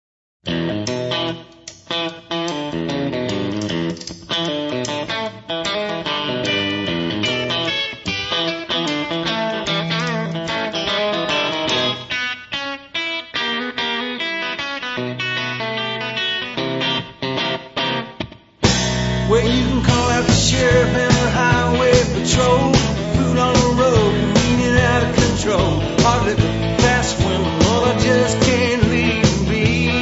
chitarra, mandolino
pianoforte
tromba
violino
• registrazione sonora di musica